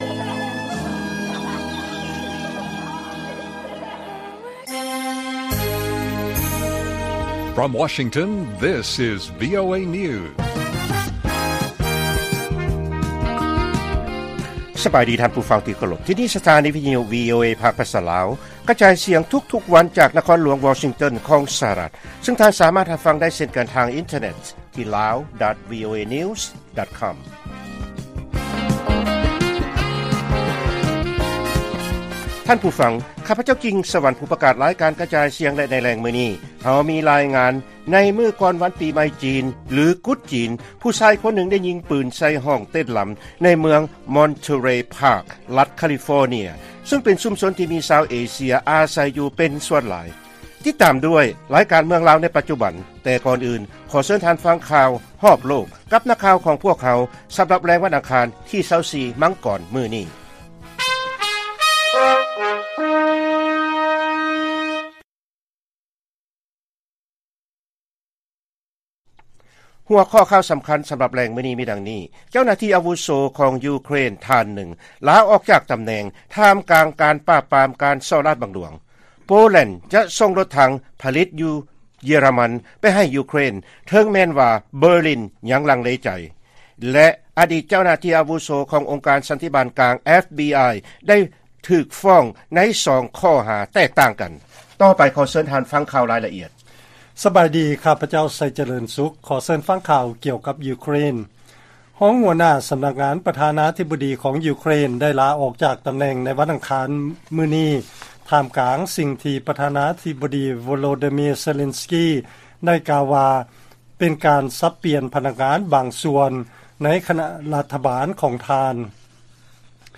ວີໂອເອພາກພາສາລາວ ກະຈາຍສຽງທຸກໆວັນ, ຫົວຂໍ້ຂ່າວສໍາຄັນໃນມື້ນີ້ມີ: 1.